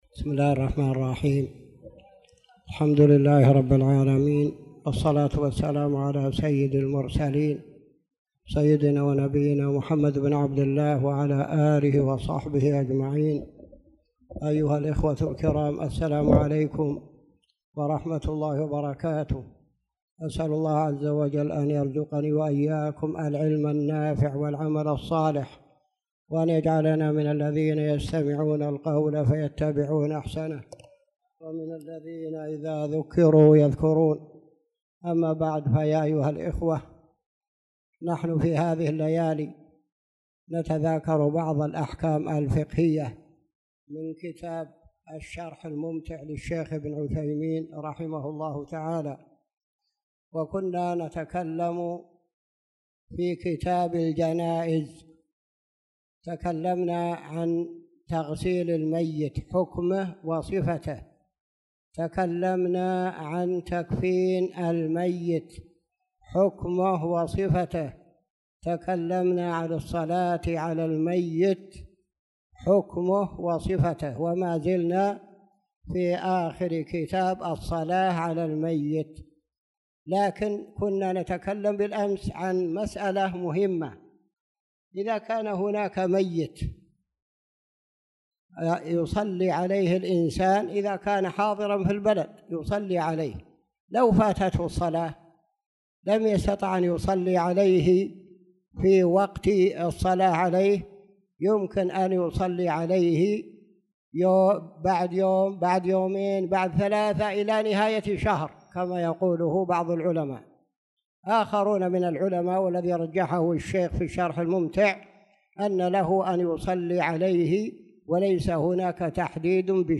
تاريخ النشر ١٢ ذو القعدة ١٤٣٧ هـ المكان: المسجد الحرام الشيخ